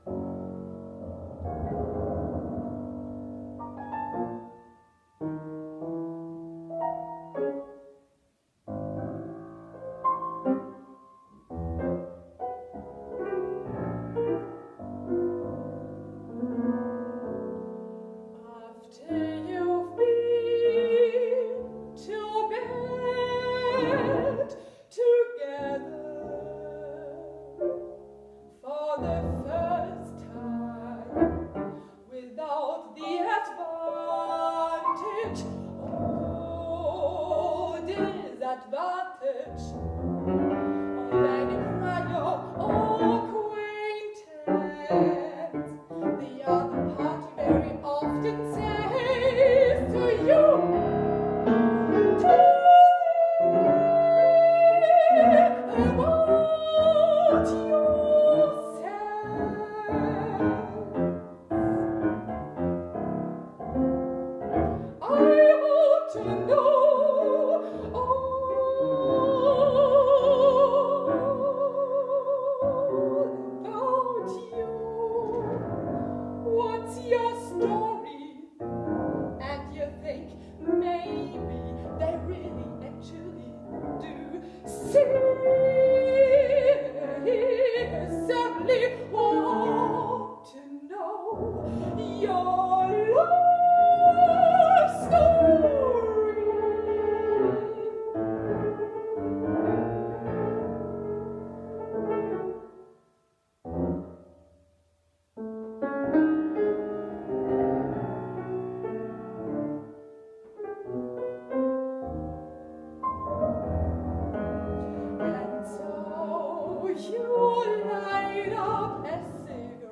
(Live- Mitschnitt)